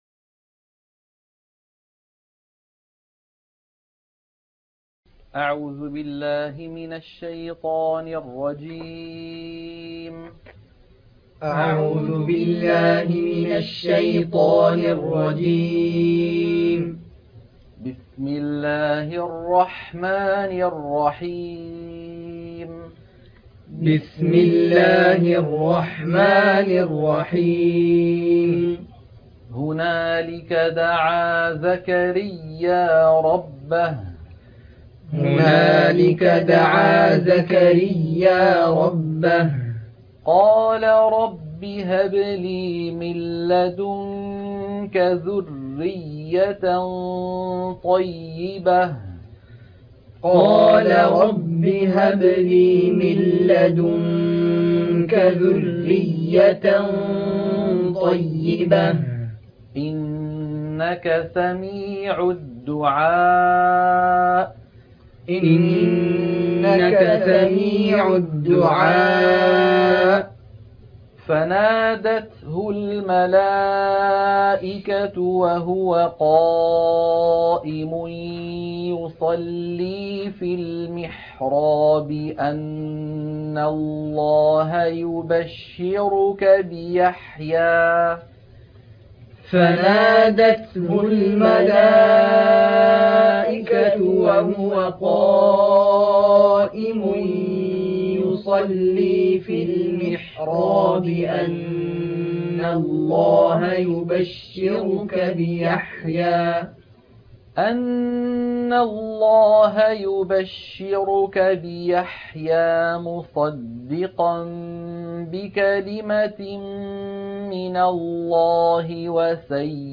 عنوان المادة تلقين سورة ءال عمران- الصفحة 55 - التلاوة المنهجية